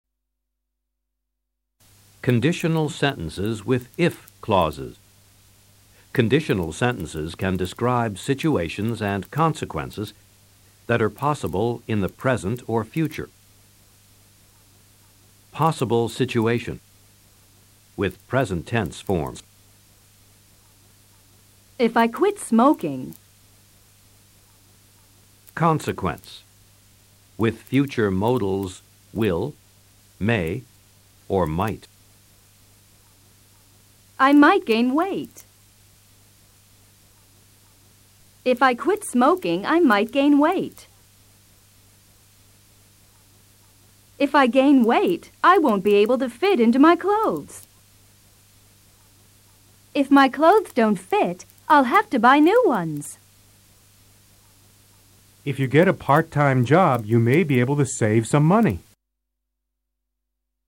Escucha a los profesores y presta atención a la formación del PRIMER CONDICIONAL.